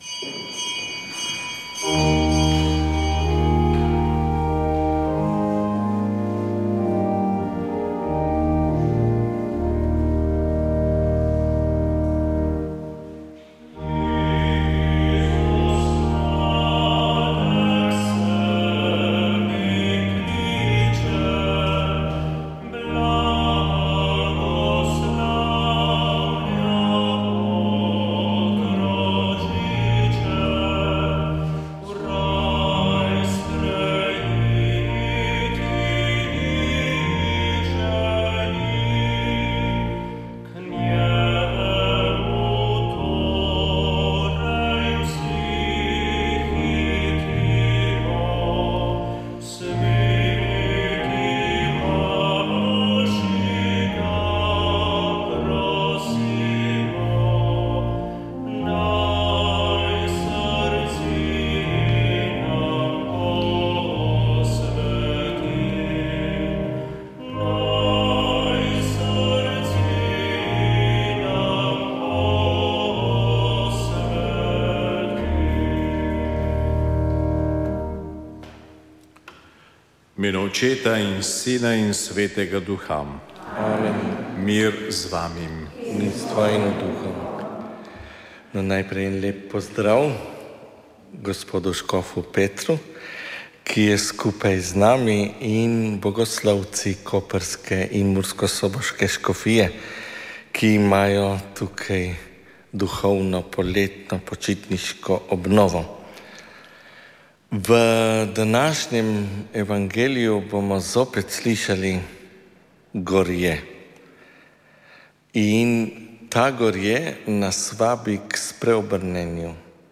Sv. maša iz cerkve sv. Marka na Markovcu v Kopru 25. 8.
peli so bogoslovci koprske in murskosoboške škofije.